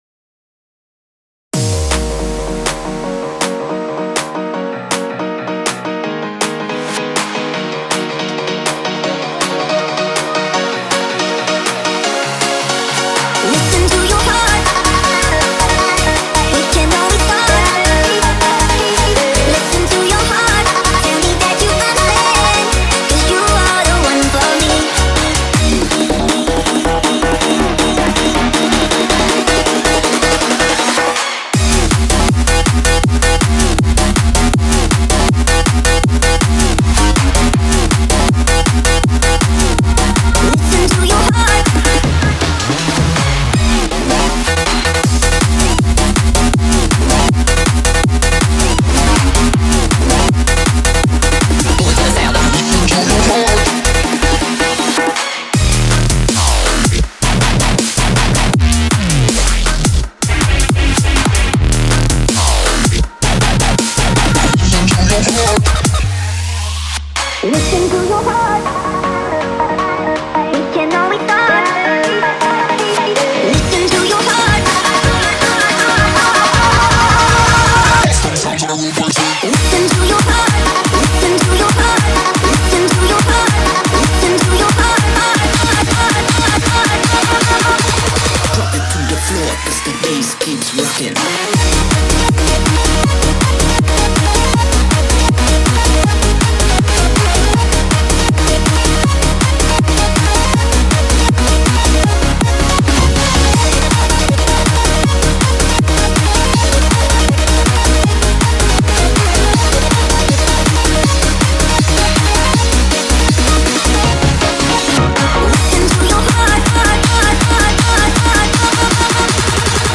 BPM160
Audio QualityPerfect (Low Quality)